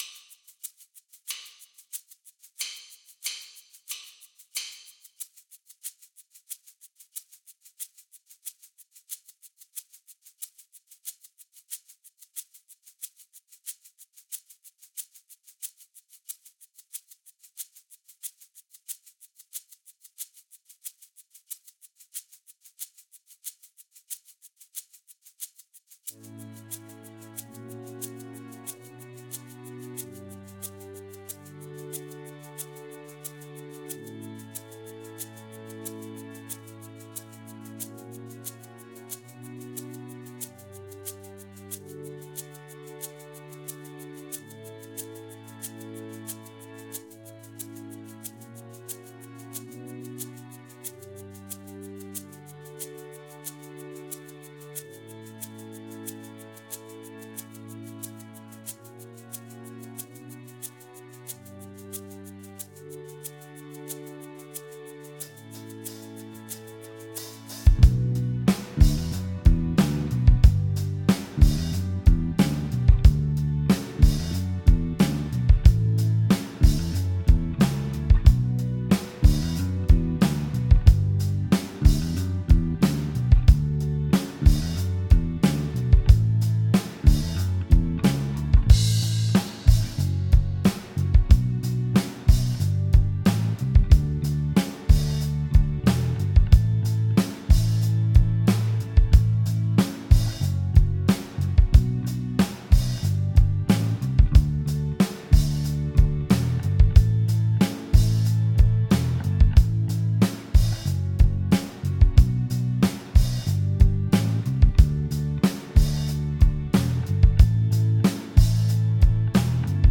BPM : 92
Tuning : E
Without vocals